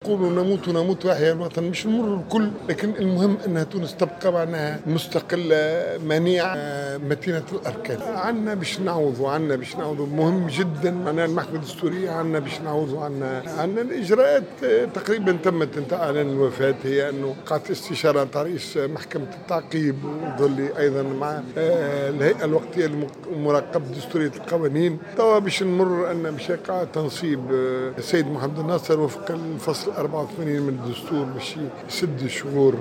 وأضاف بن أحمد في تصريح لمراسلنا بالبرلمان، أنه بعد اتمام جميع الاجراءات والمتمثلة في استشارة كل من رئيس محكمة التعقيب والهيئة الوقتية لمراقبة دستورية القوانين، فإنه سيتمّ على الساعة الثانية ظهرا ووفقا للفصل 84 من الدستور، سد الشغور النهائي.